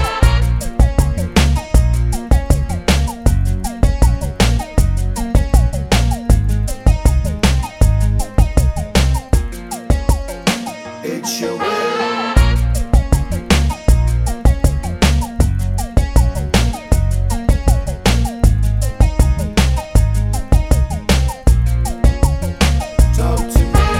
no Backing Vocals Reggae 3:43 Buy £1.50